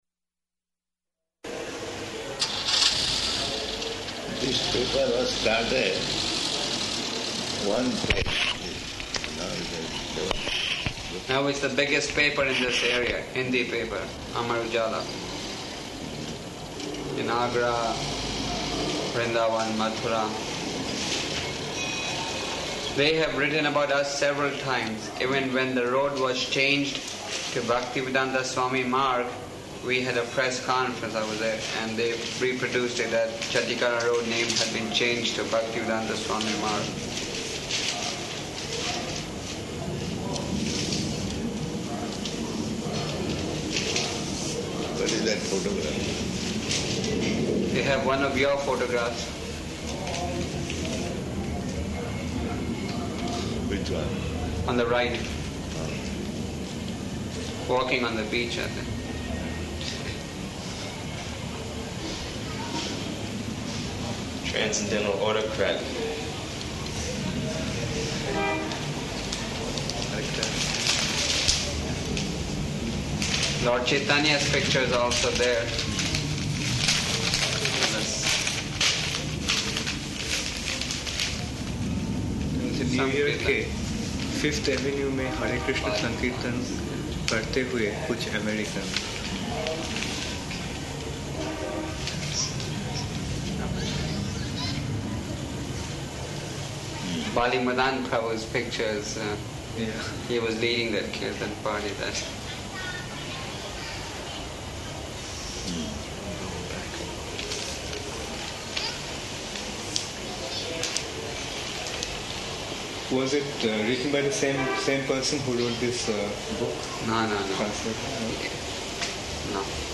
GBC Meets with Śrīla Prabhupāda --:-- --:-- Type: Conversation Dated: May 28th 1977 Location: Vṛndāvana Audio file: 770528ME.VRN.mp3 Prabhupāda: This paper was started, one page, this.